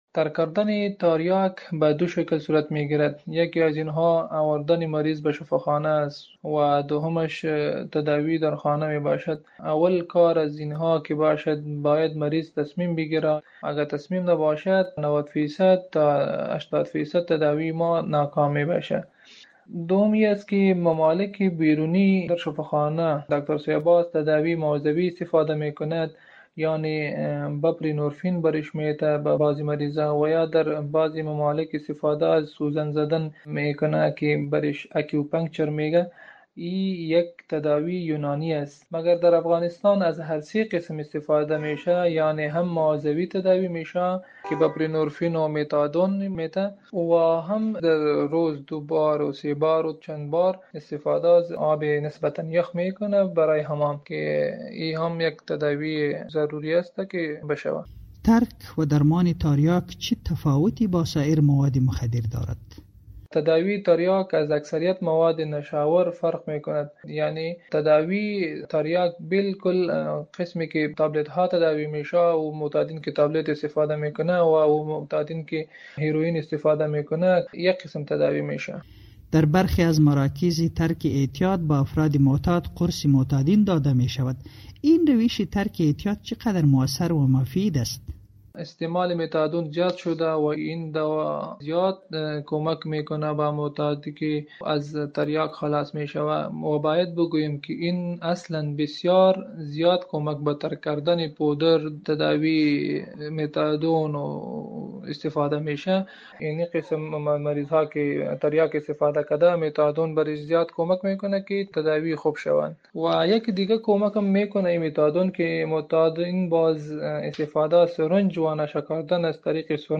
مصاحبه کرده است.